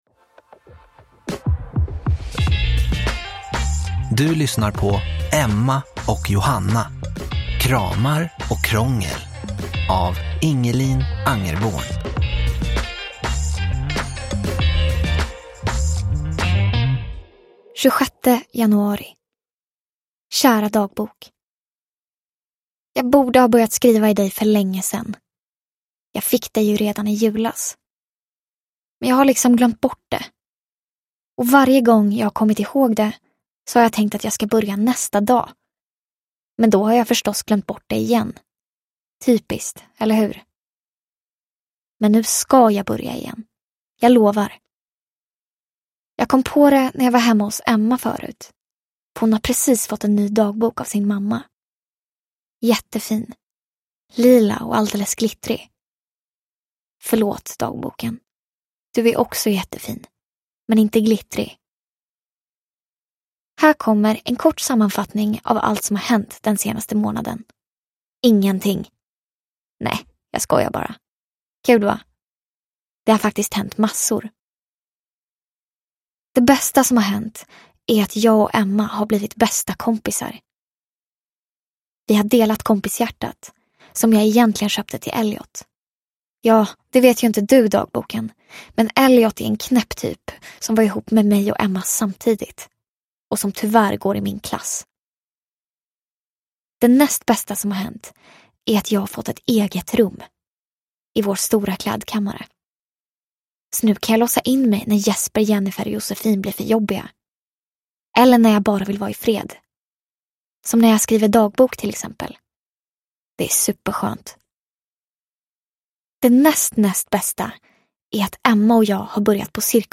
Kramar och krångel – Ljudbok – Laddas ner